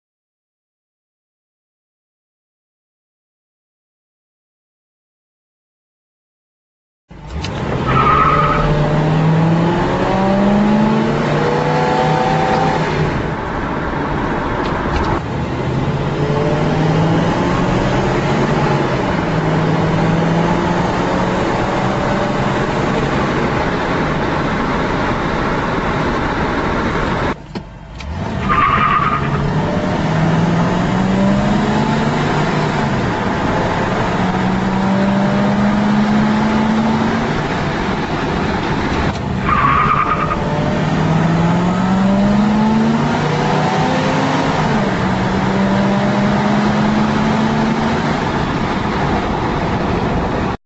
Not wanting to dig into the linkage too much, I simply removed the lockout function and for the first time since I owned that car, I experienced the roar of 400 cubic inches
sucking wind through all four throats of the carburetor.